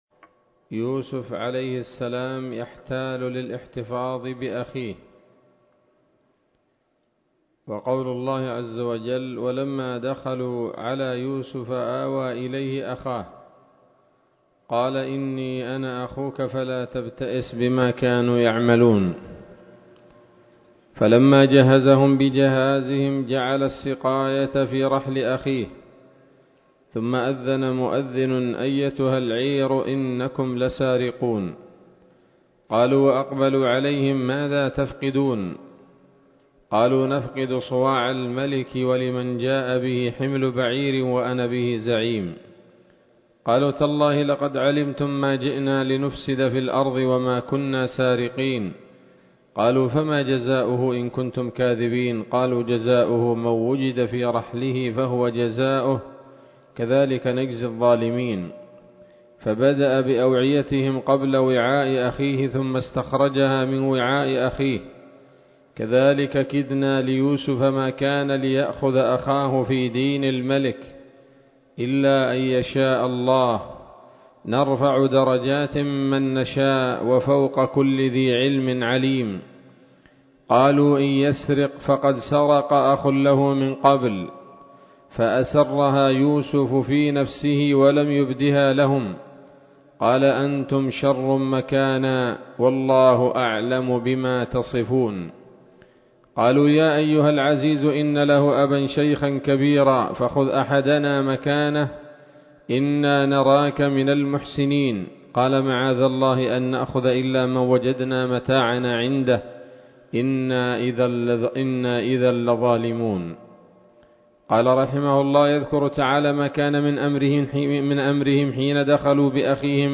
الدرس الحادي والسبعون من قصص الأنبياء لابن كثير رحمه الله تعالى